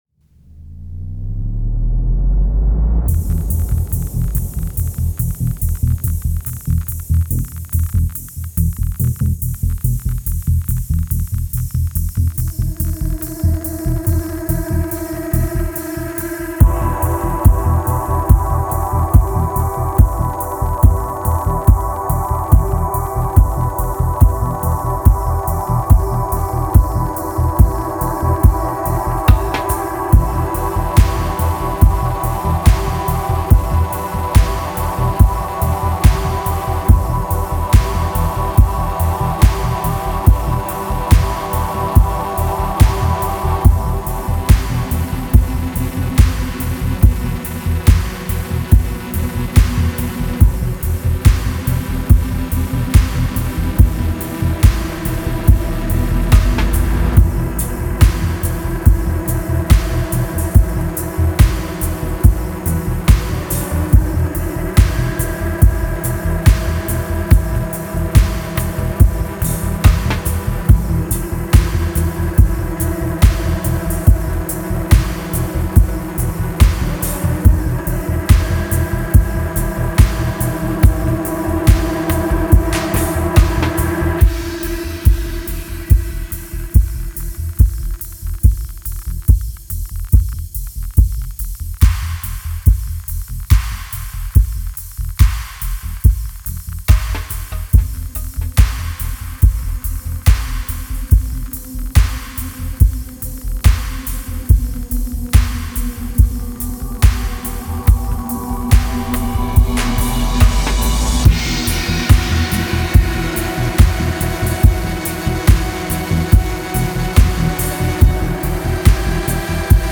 Darkened side streets awash with low frequency anxiety.